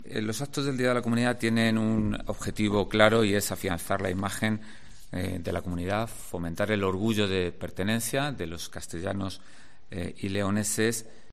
El Delegado, José Francisco Hernández Herrero, sobre el día de Castilla y León
Deporte, gastronomía y ocio para celebrar el orgullo de ser castellano-leoneses como ha destacado el delegado territorial de la Junta de Castilla y León en Ávila, José Francisco Hernández Herrero en la presentación del evento.